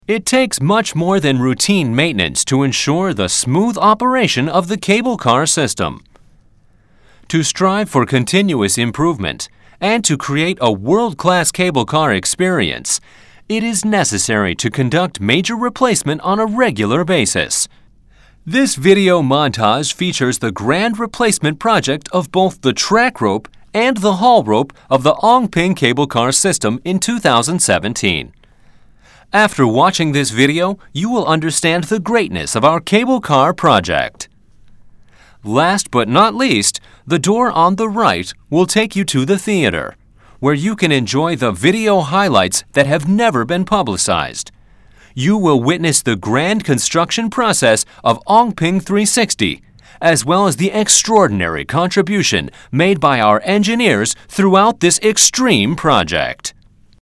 Cable Car Discovery Centre Audio Guide (English)